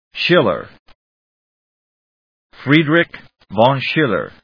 音節Schil・ler 発音記号・読み方/ʃílɚ‐lə/, (Johann Christoph) Friedrich von /joʊhάːn krístɔːf fríːdrɪkjəʊhˈæn krístɔf‐/発音を聞く